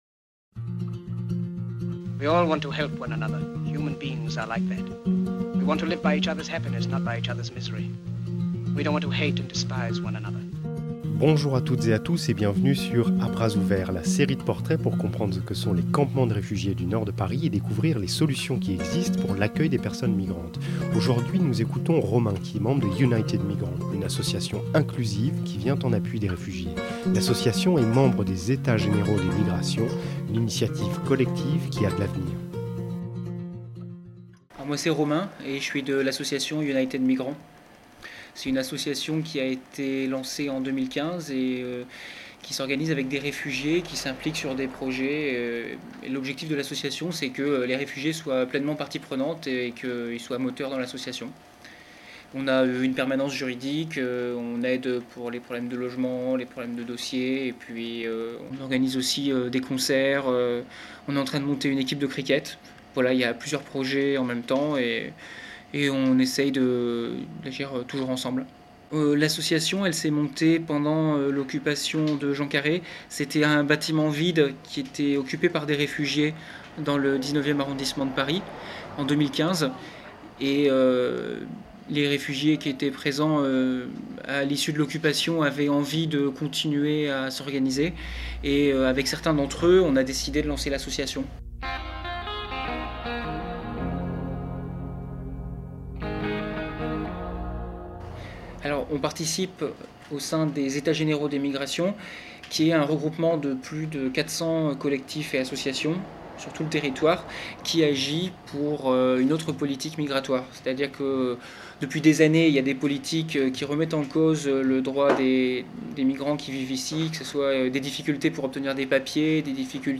Musique : Feu! Chatterton - Fou à lier